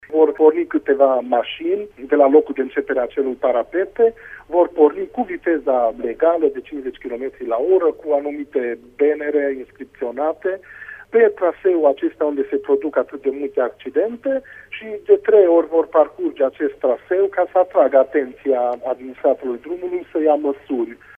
Primarul localității mureșene, Sofalvi Szabolcs: